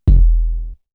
ZAY_808_06_E.wav